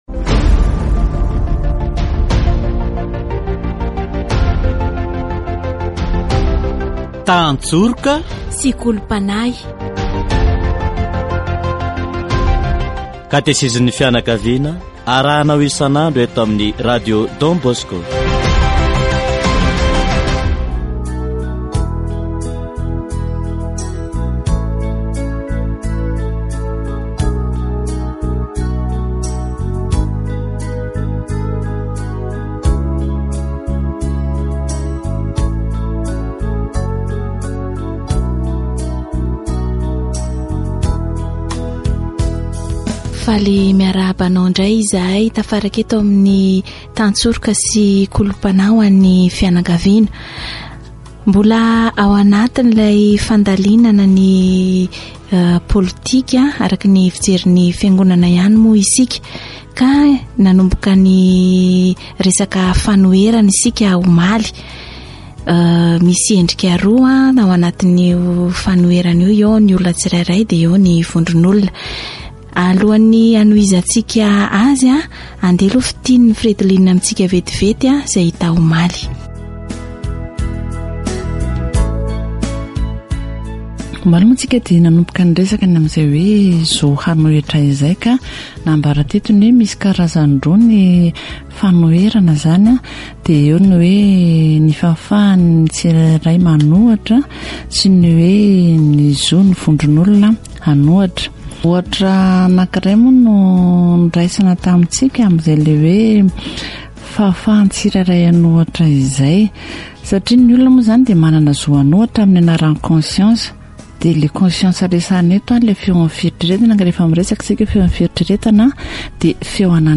Katesizy momba ny politika araka ny fijerin'ny Eglizy